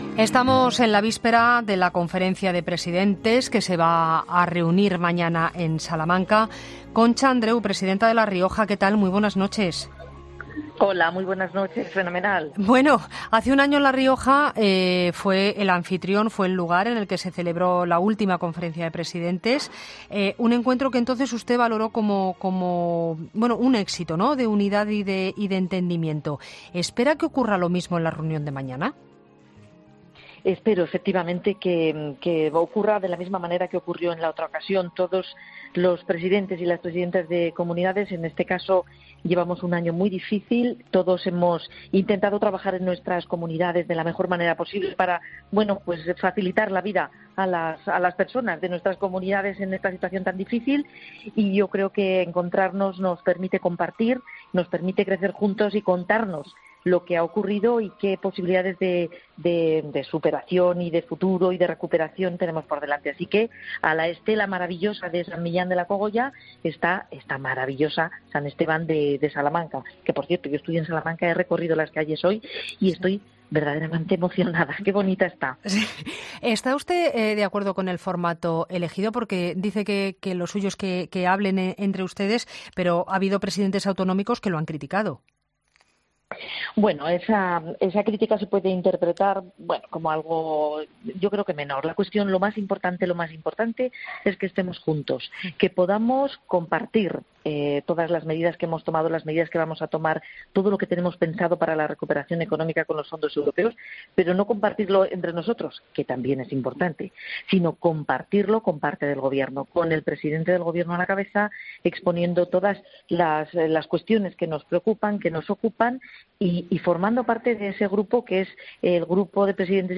La presidenta de La Rioja, Concha Andreu, ha pasado por los micrófonos de 'La Linterna' para analizar la próxima conferencia de presidentes